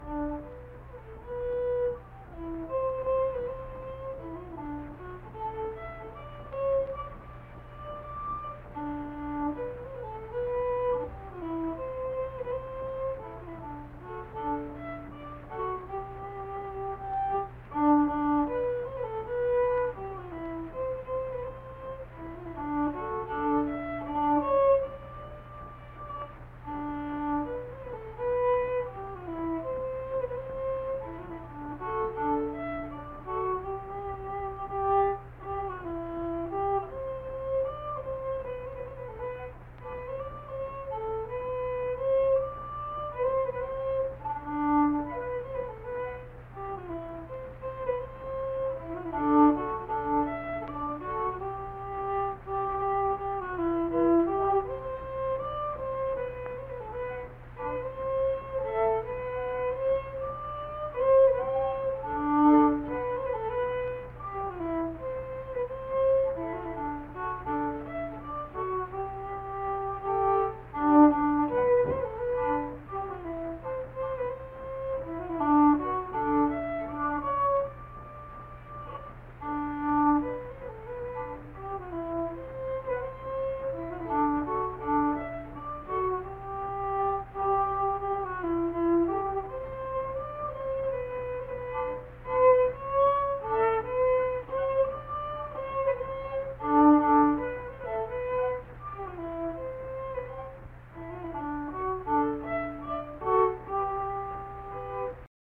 Unaccompanied fiddle music performance
Verse-refrain 2(2).
Instrumental Music
Fiddle